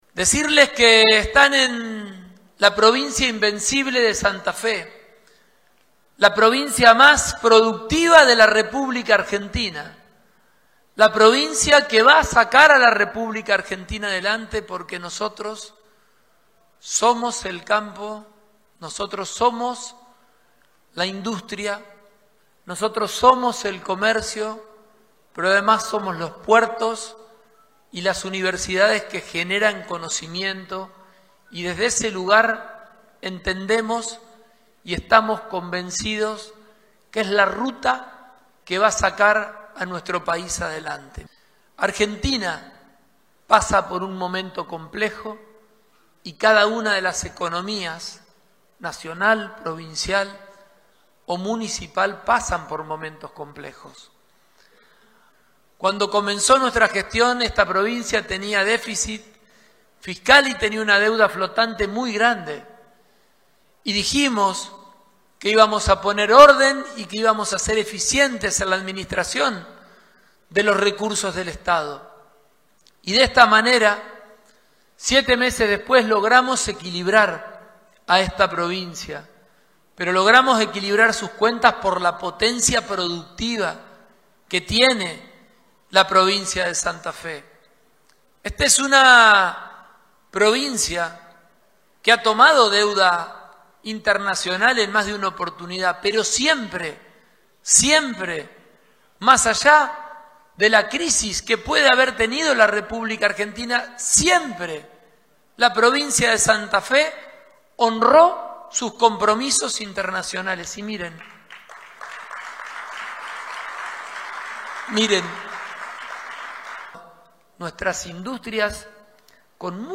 Pullaro encabezó la apertura del Santa Fe Business Forum, encuentro que busca promover el intercambio comercial y las inversiones internacionales.